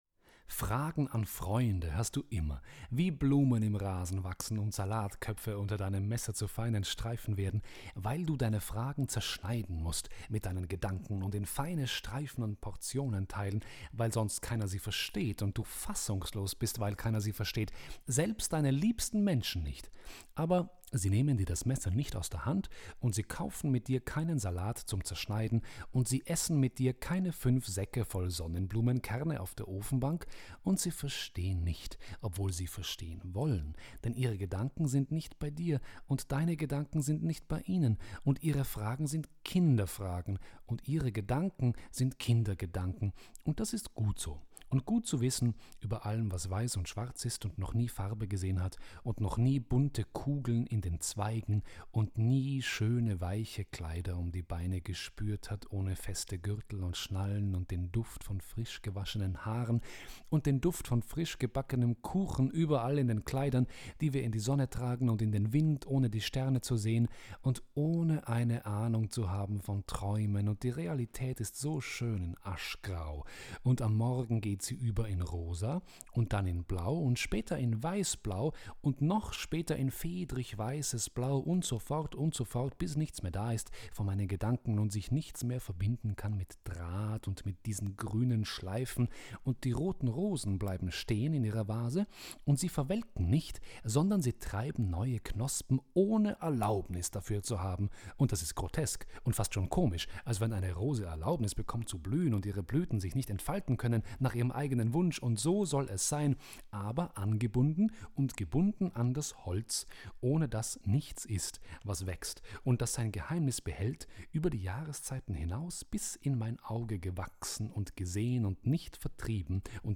Im Himmel gebadetes Obst. Buch und Hörbuch ( 1996)